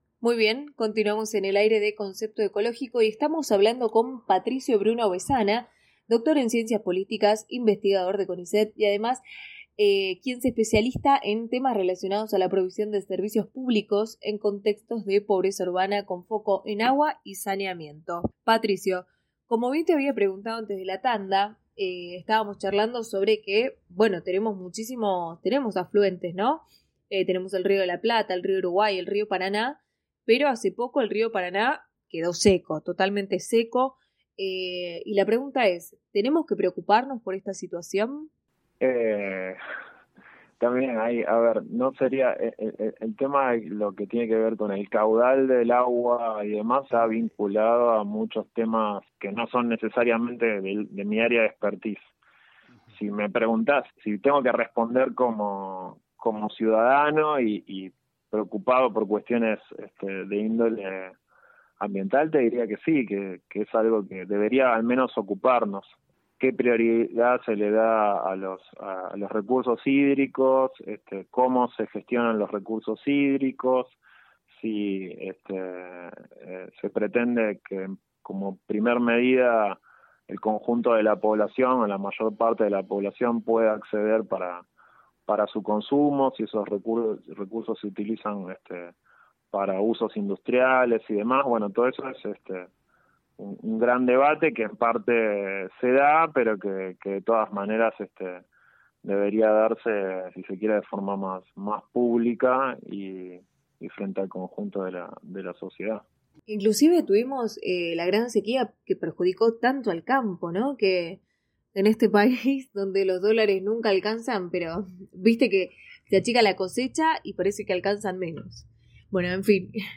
Hacia el final de la conversación se hizo foco en la provisión de servicios en barrios populares. El programa es transmitido por 12 radios a lo largo del país y versa sobre temas vinculados al ambiente y la ecología.
Entrevista parte 1 Entrevista parte 2 Comparte esto: Compartir en X (Se abre en una ventana nueva) X Comparte en Facebook (Se abre en una ventana nueva) Facebook Me gusta Cargando...